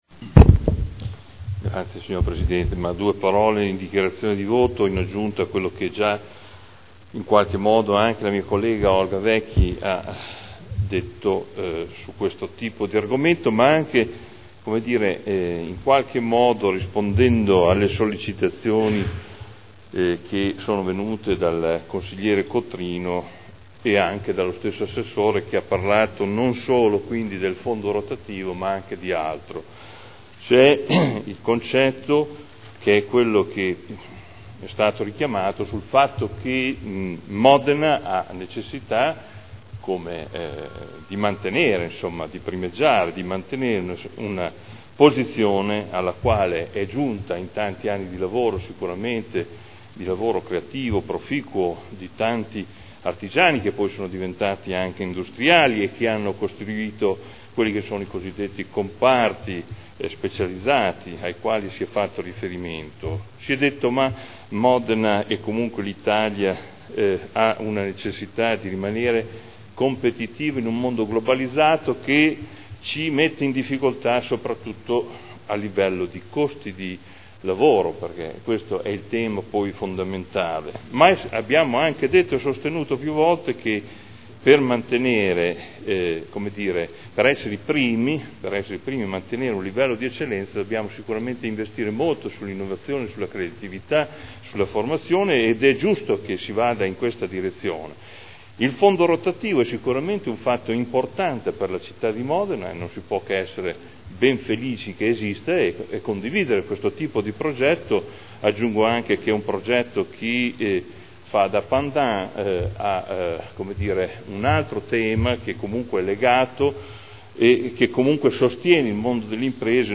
Seduta del 19/09/2011. Dichiarazione di voto proposta di deliberazione. Fondo provinciale per il sostegno all’innovazione delle imprese – Approvazione dello schema di convenzione per il rinnovo del fondo rotativo